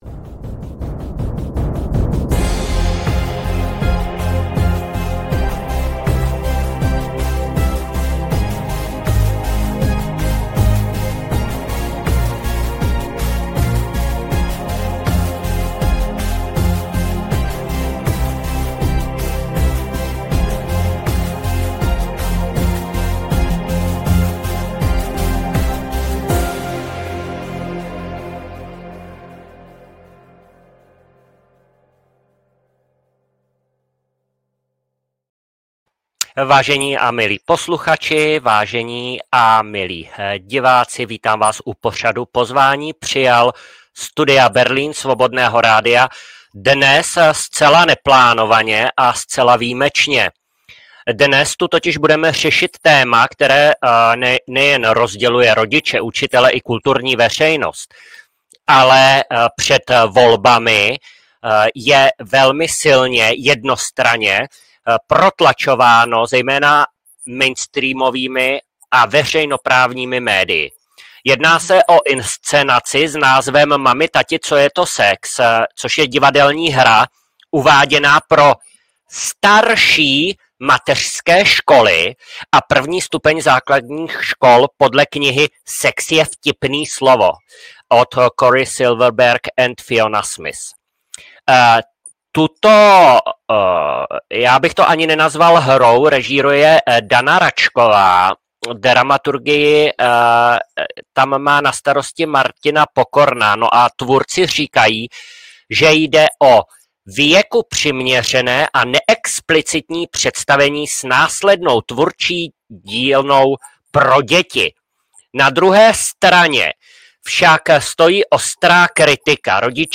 Dnešní rozhovor